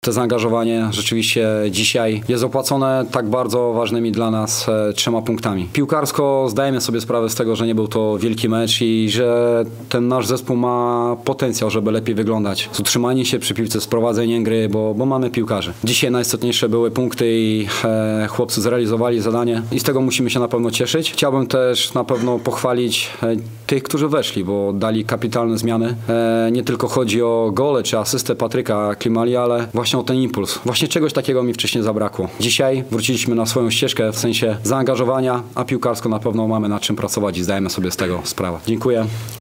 na pomeczowej konferencji